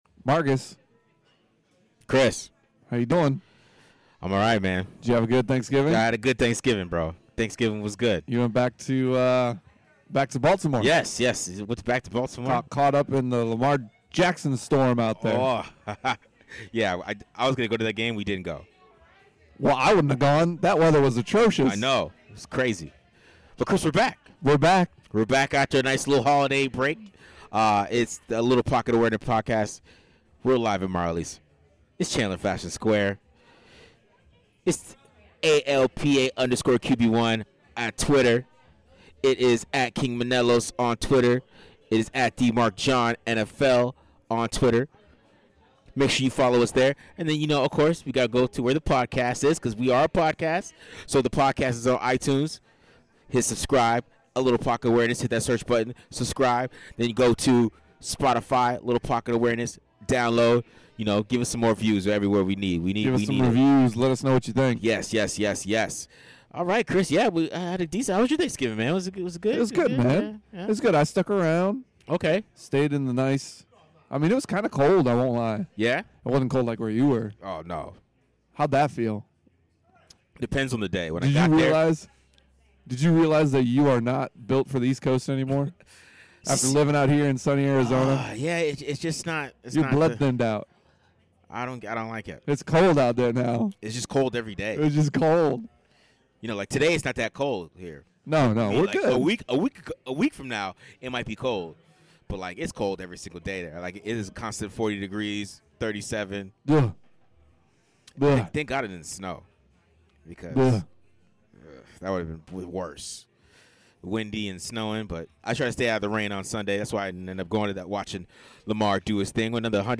We are back!!! Episode 40 of ALPA live from Marjerles at Chandler Fashion Square